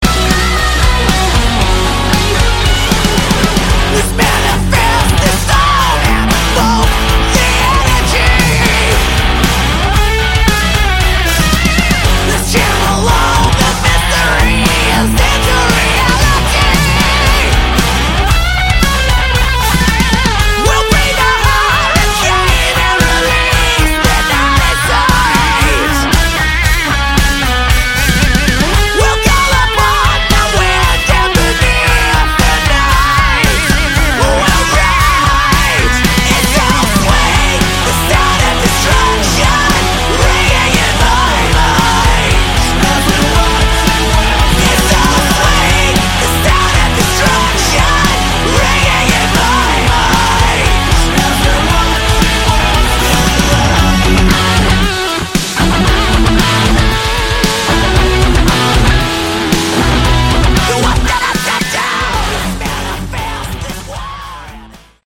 Category: 80s Hard Rock